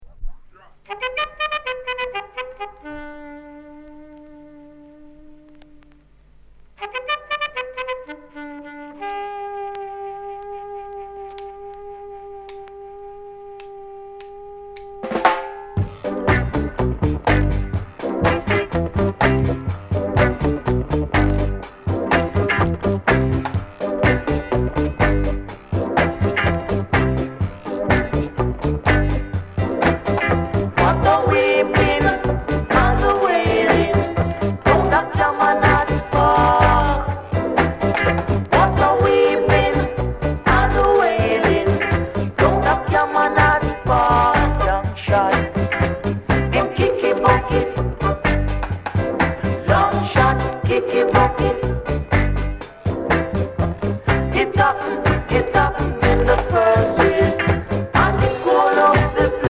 Sound Condition A SIDE VG
NICE ROCKSTEADY